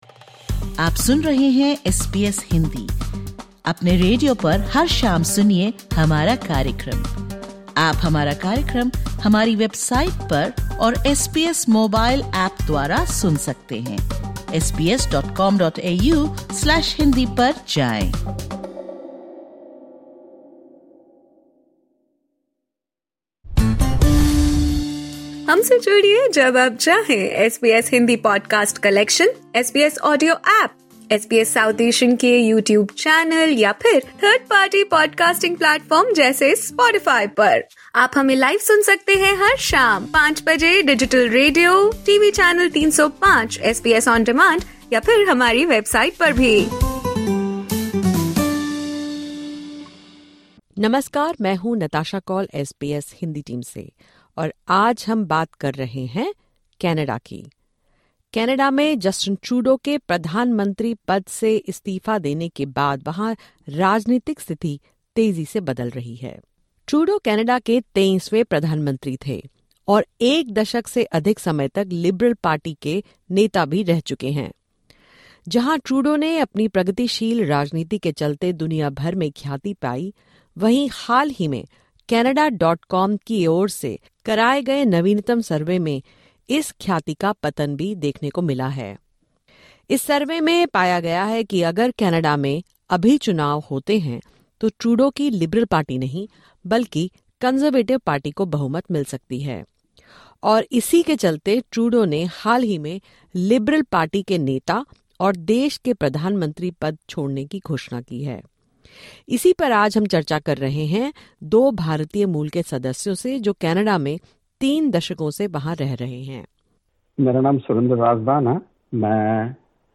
As speculation swirls around Canadian Prime Minister Justin Trudeau’s future as leader of the Liberal Party, a few names, including Anita Anand, have emerged as potential frontrunners to succeed him. In this podcast episode, we speak with Indian-Canadians about Trudeau's declining popularity, the key election issues facing Canada, and the possibility of the country electing its first Indian-origin Prime Minister.